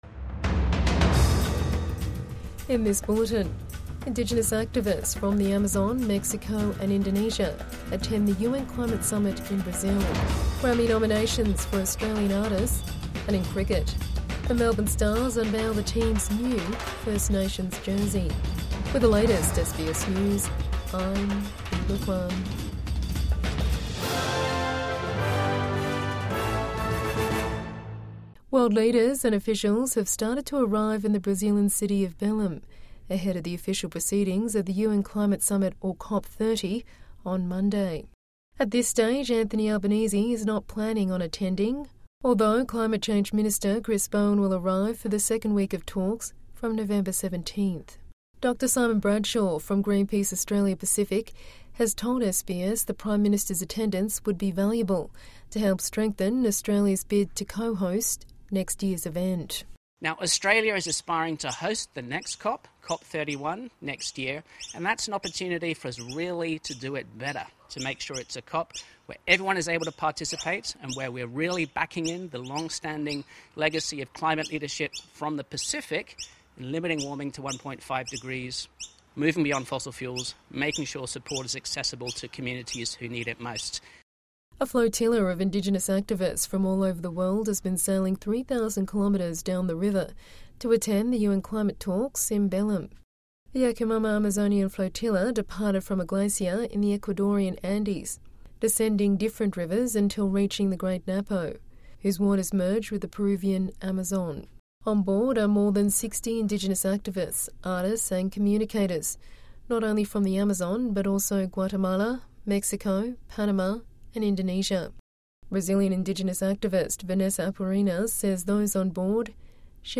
Midday News Bulletin 8 November 2025